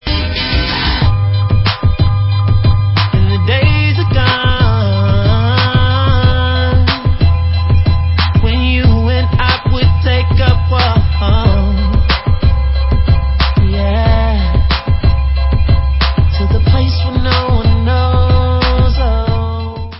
sledovat novinky v oddělení Pop/Rhytm & Blues